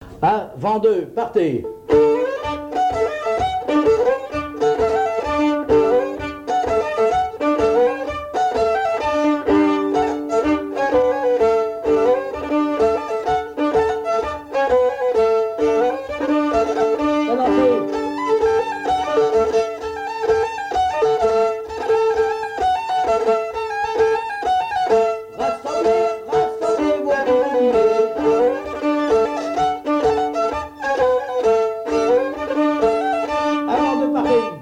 Avant-deux
Pièce musicale inédite